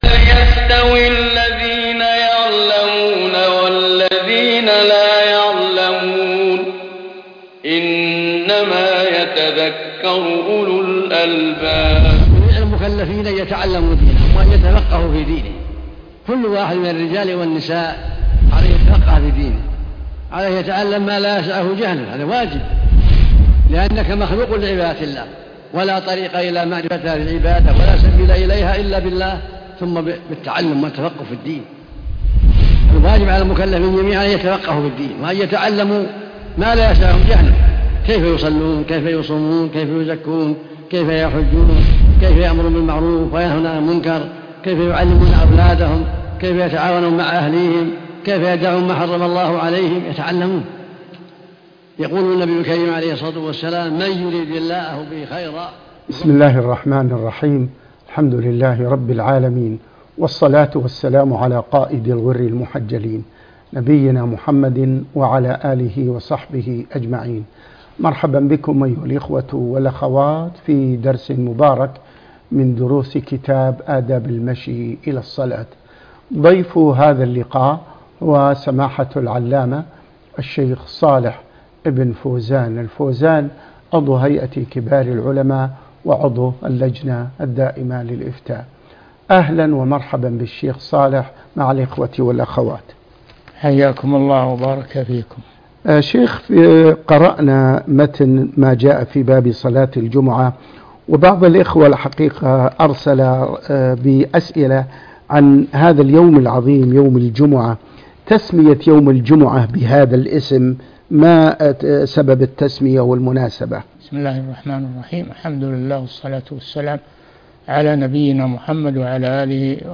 الدرس (10) آداب المشي إلى الصلاة 4 - البناء العلمي - الشيخ صالح بن فوزان الفوازان